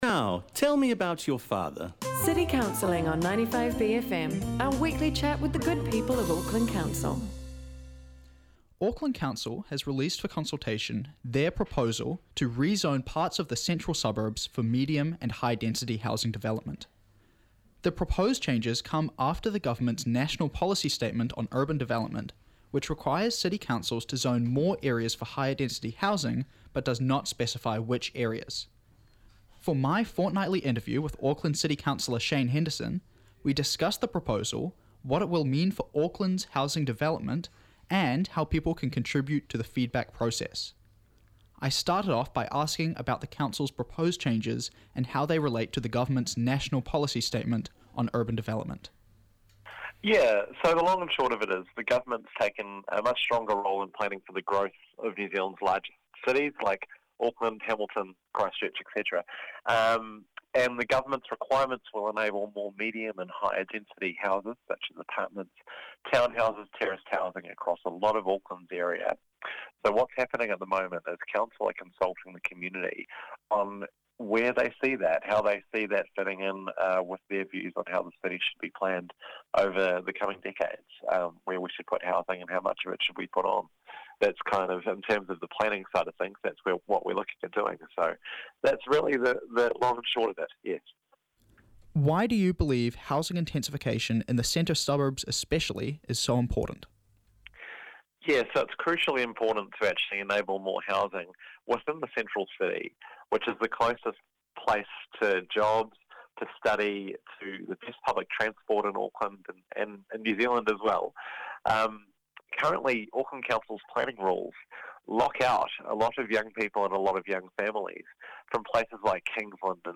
Two alternating Auckland City Councillor's Julie Fairey and Shane Henderson tell us the latest in Council news every Thursday on The Wire.